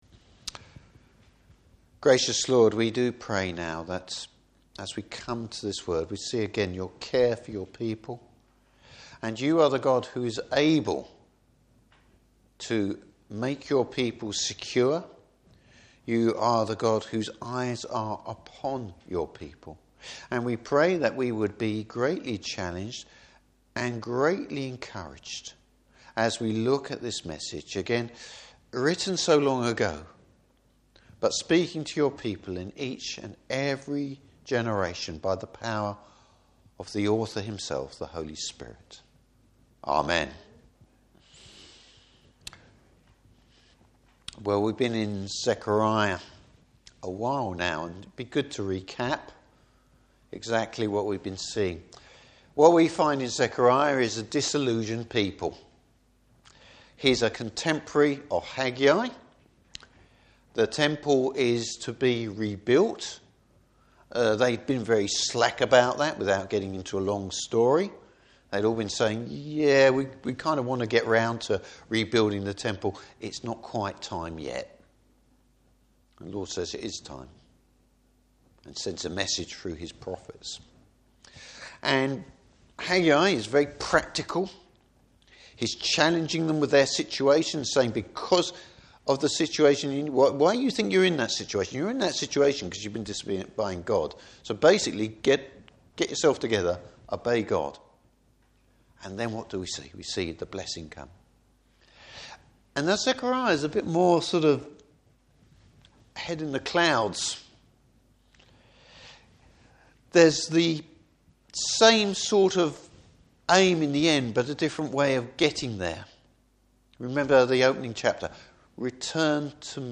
Service Type: Evening Service The Lord’s concern for his people.